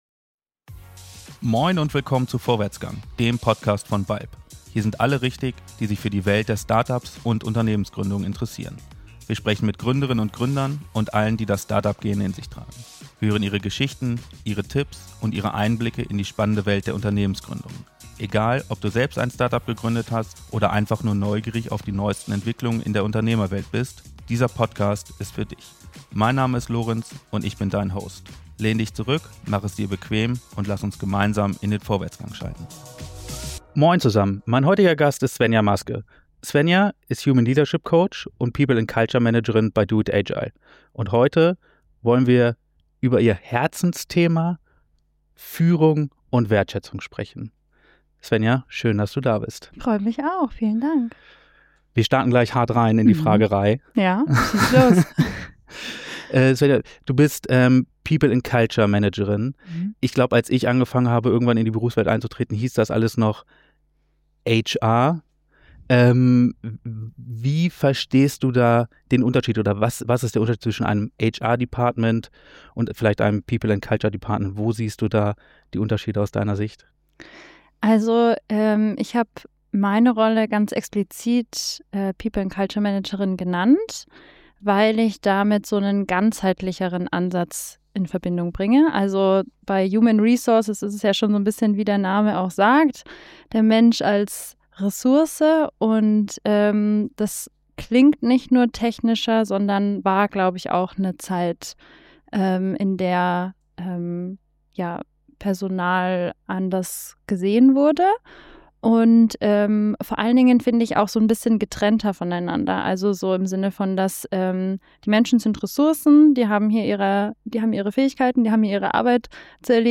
Wir sprechen über Wertschätzung – jenseits von Buzzword-Phrasen – und darüber, wie es gelingt, Menschen als ganze Personen zu sehen.